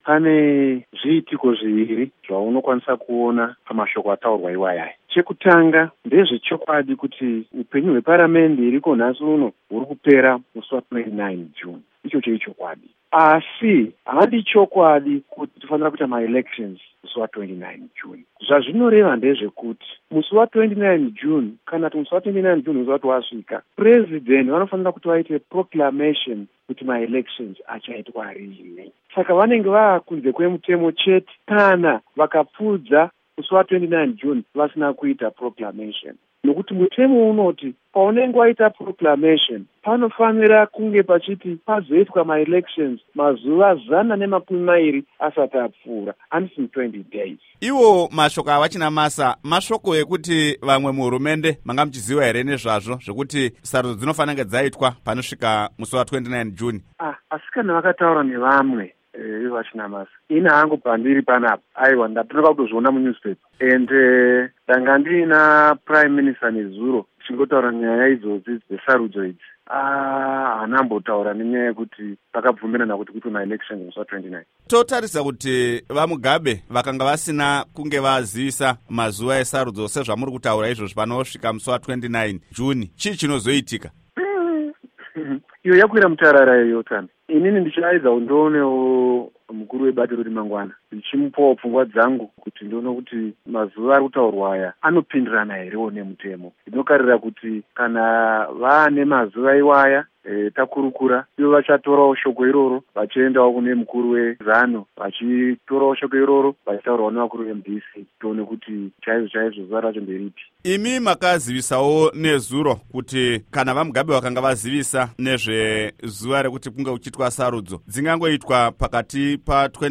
Hurukuro naVaEric Matinenga